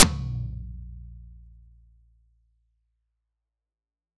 Обнаружил странный артефакт на треке замороженного тома от AD2 - перед нормальной волной какая-то квадратная хрень.
Вытащил этот клип и просмотрел в WaveLab -треск в левом канале перед звуком и всё.